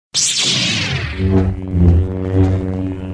lightsaber-sound-effect-hq-hd_aSD6hZa.mp3